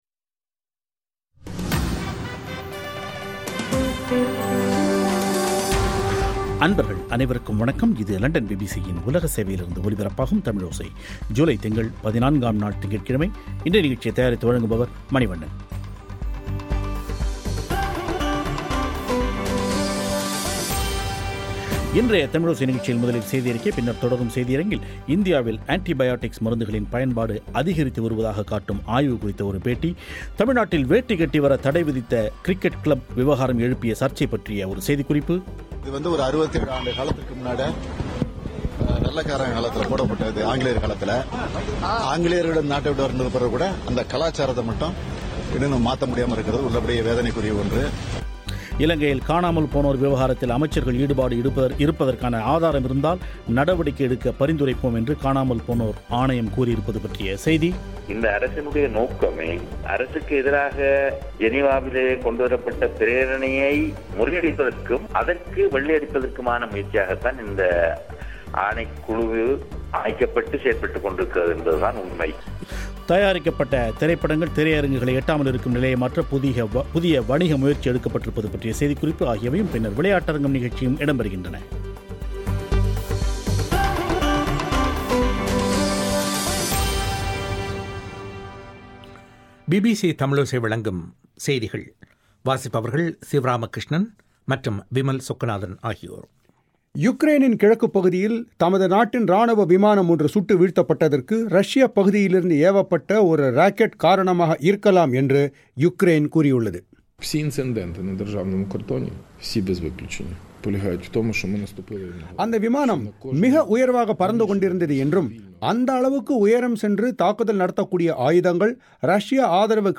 இன்றைய தமிழோசை நிகழ்ச்சியில் இந்தியாவில் அண்டி ப்யாடிக்ஸ் மருந்துகளின் பயன்பாடு அதிகரித்து வருவதாக்க் காட்டும் ஆய்வு குறித்த ஒரு பேட்டி